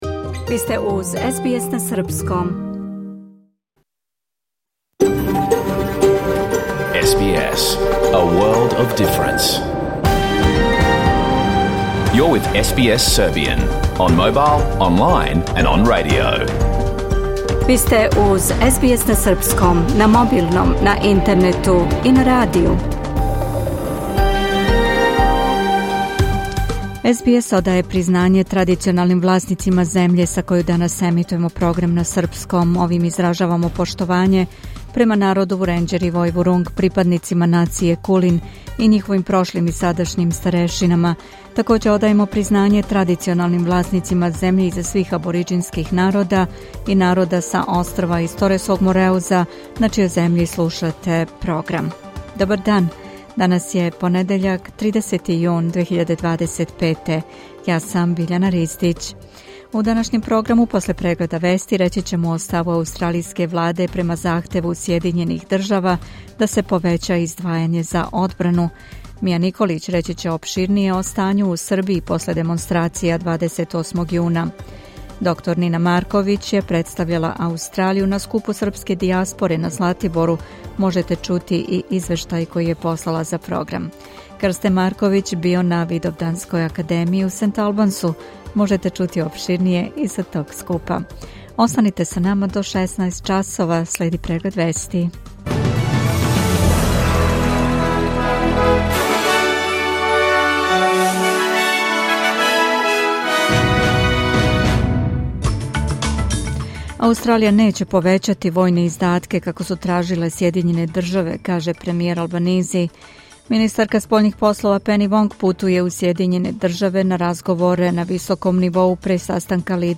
Serbian News Bulletin